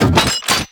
JackHammer_Reload.wav